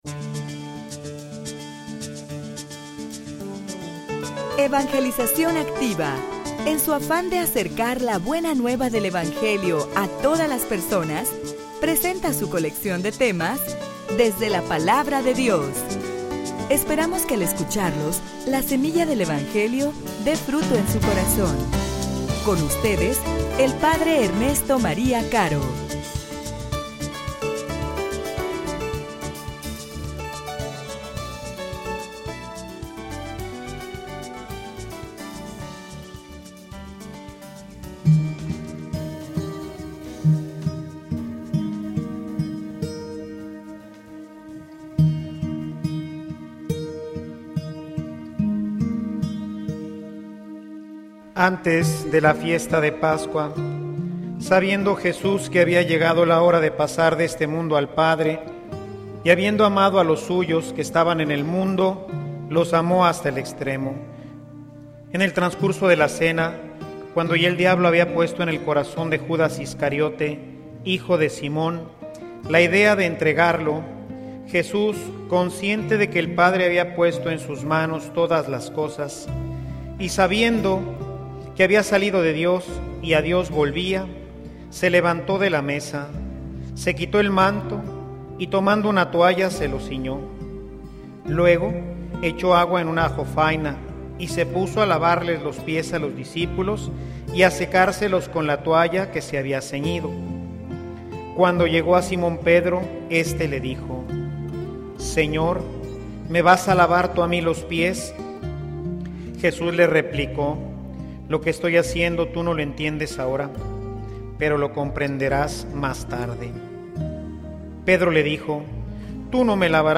homilia_Un_sacerdote_como_Jesus.mp3